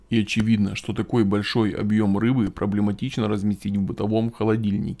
Помогите пожалуйста определить происхождение данного звука (щелчок)? Микрофон конденсаторный SE ELECTRONICS X1 S, звуковуха M-AUDIO AIR 192|8, мониторы PreSonus Eris E44, комп хороший.
Настройки: на микрофоне 0 dB, на звуковухе от 3 до 5.